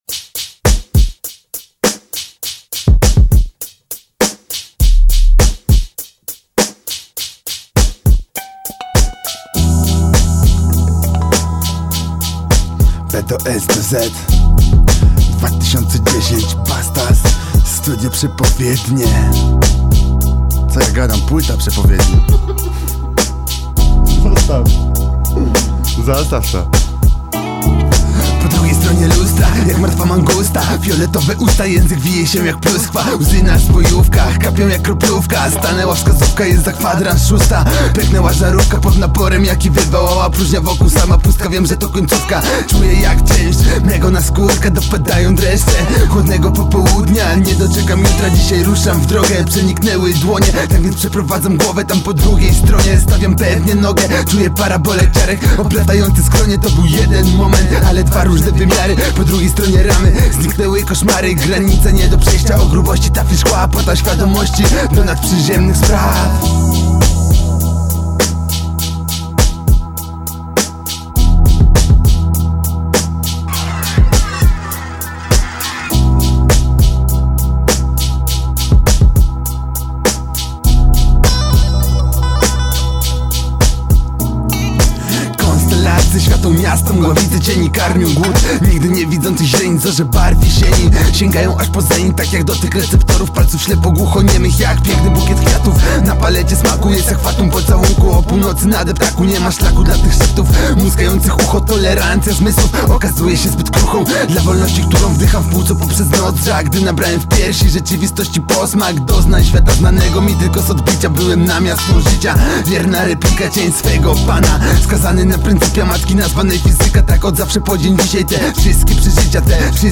hip-hop, rap